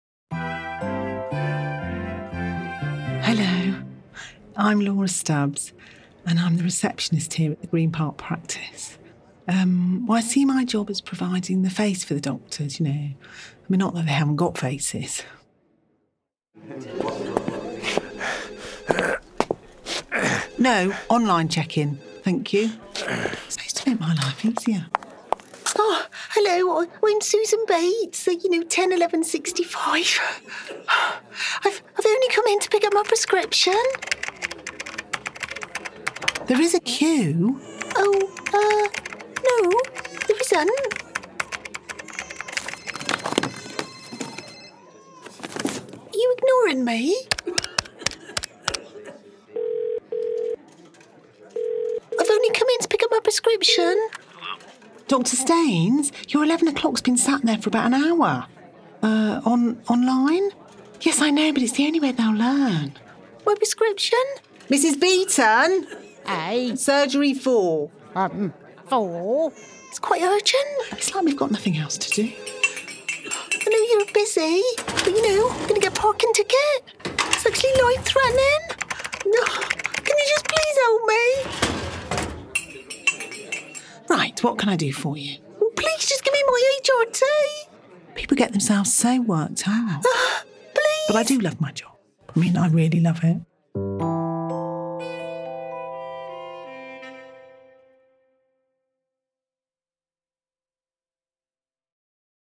• Female
Accent
• London
• Northern
Showing: Comedy Clips
Acting-Voice-Reel-finished-June-2018-1.mp3